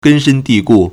根深蒂固 gēn shēn dì gù
gen1shen1di4gu4.mp3